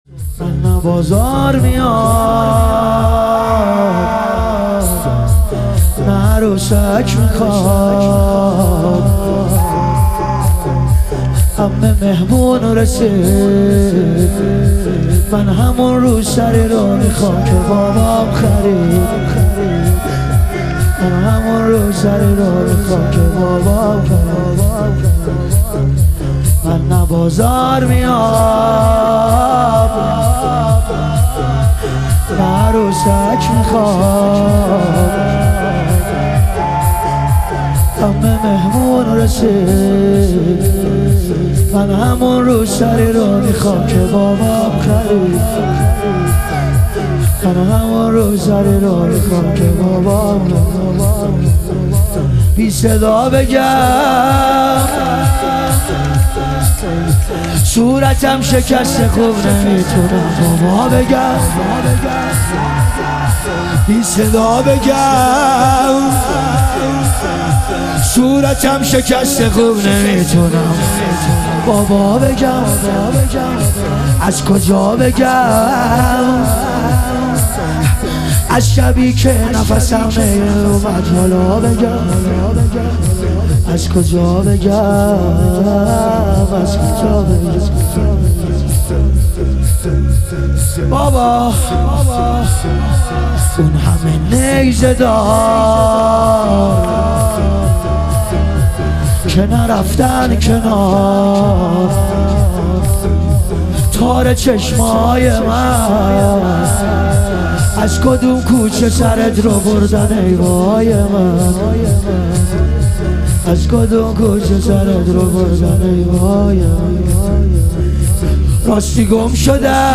ظهور وجود مقدس حضرت رقیه علیها سلام - لطمه زنی